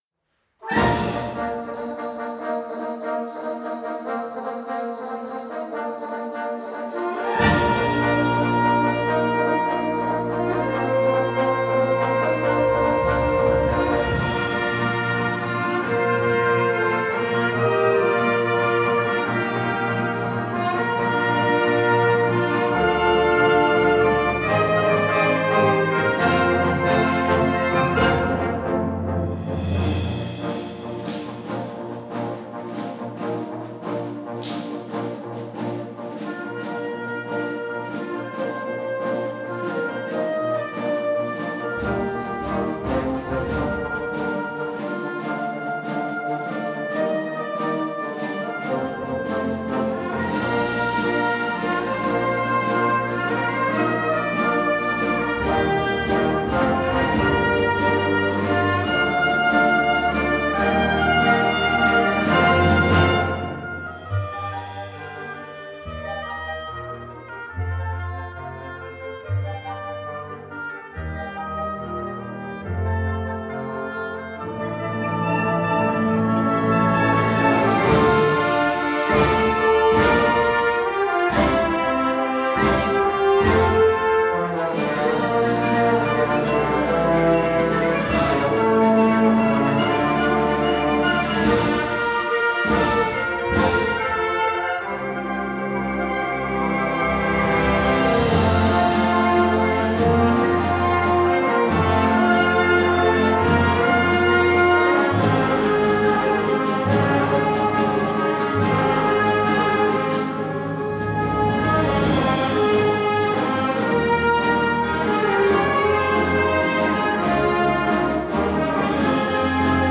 Gattung: Ouvertüre für Blasorchester
Besetzung: Blasorchester